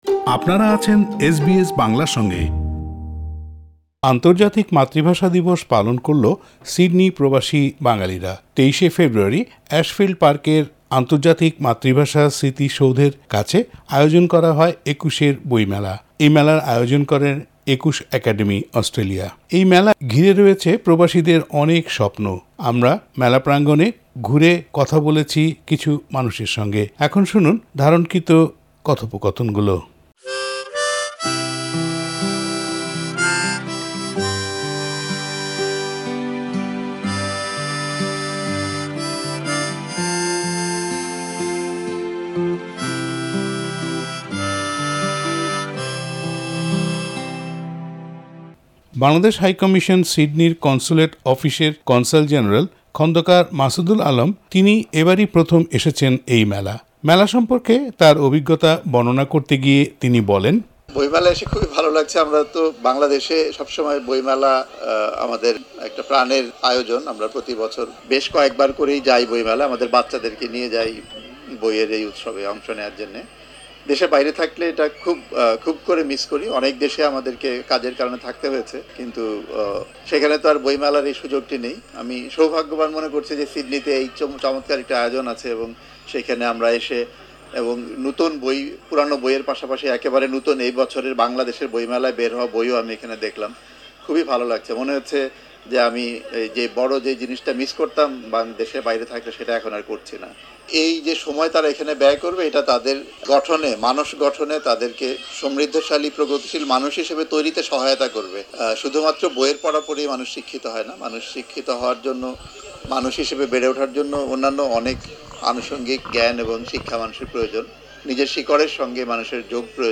একুশে একাডেমি অস্ট্রেলিয়ার উদ্যোগে সিডনির অ্যাশফিল্ড পার্কে গত ২৩ ফেব্রুয়ারি পালিত হয় আন্তর্জাতিক মাতৃভাষা দিবস। এ উপলক্ষে সেখানে আয়োজন করা হয় একুশের বইমেলার। প্রবাসী বাংলাভাষীদের কয়েকজন কথা বলেছেন এসবিএস বাংলার সঙ্গে।